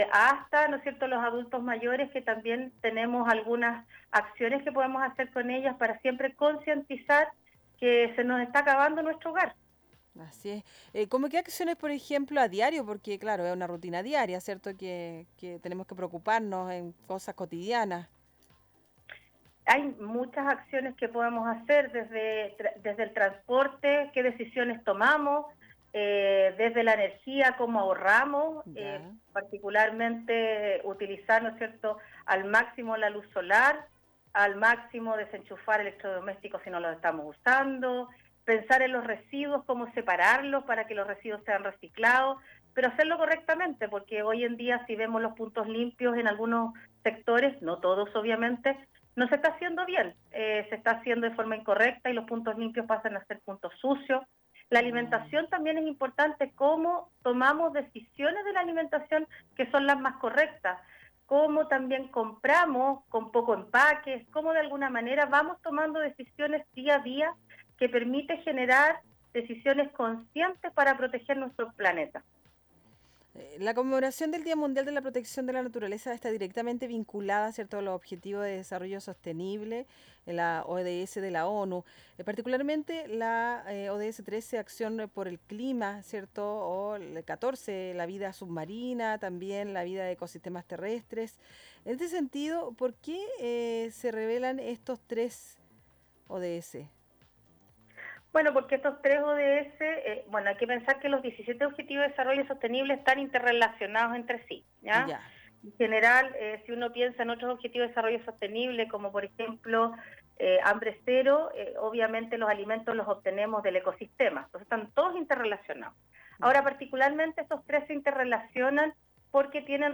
Facultad de Ciencias Ambientales | Entrevista